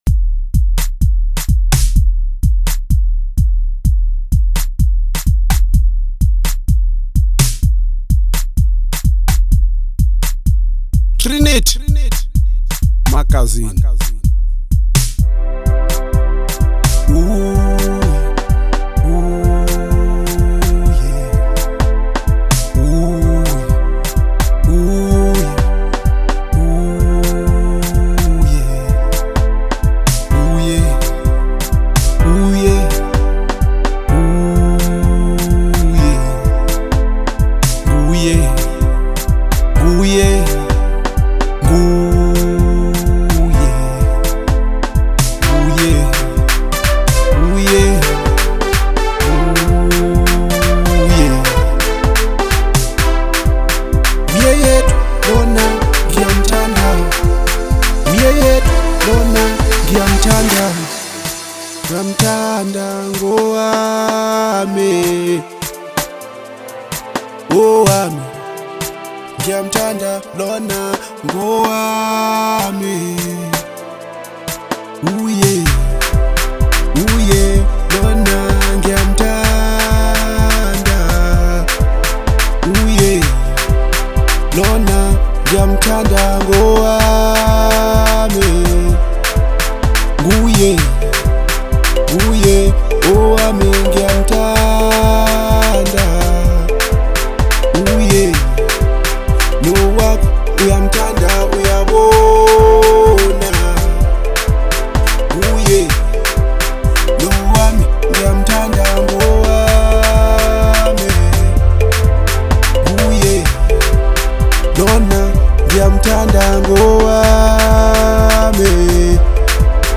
heartfelt Bolo House love song